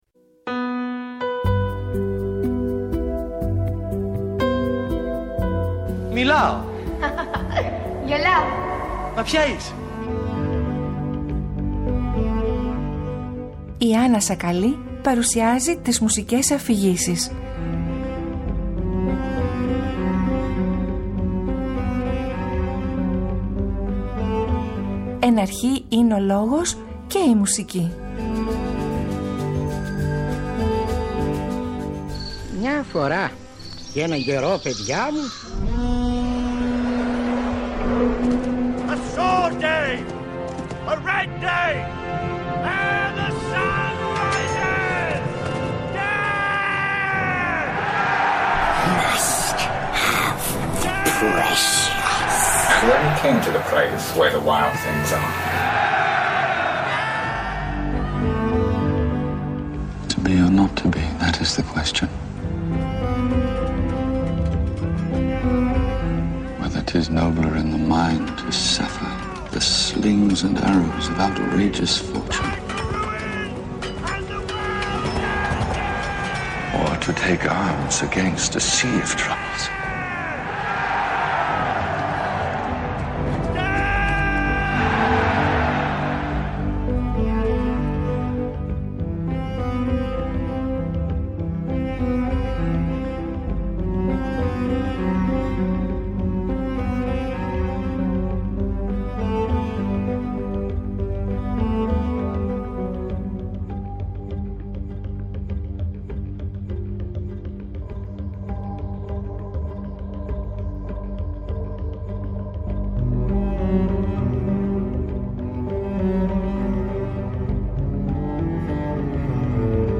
διαβάζουν το διήγημα “Ψέματα” του Ρώσου συγγραφέα Αρκάδιου Τιμοφέγιεβιτς Αβερτσένκο (1881-1925)